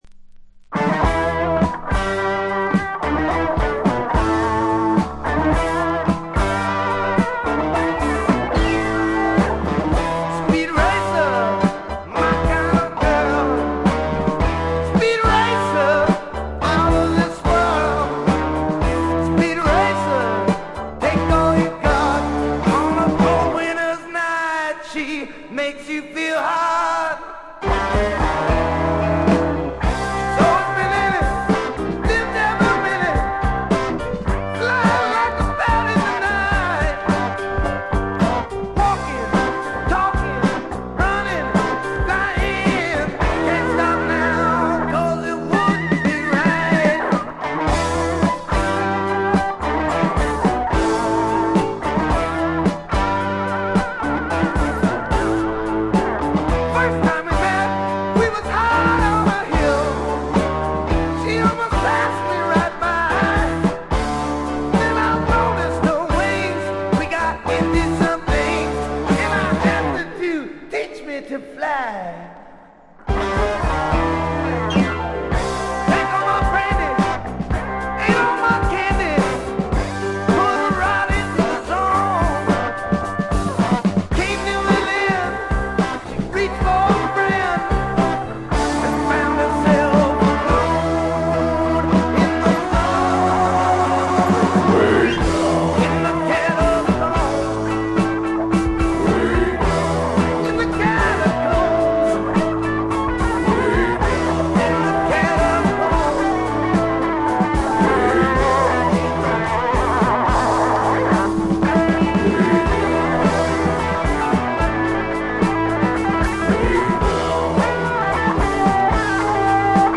わずかなノイズ感のみ。
渋みのあるヴォーカルも味わい深い88点作品。
試聴曲は現品からの取り込み音源です。